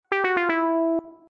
gameover.ogg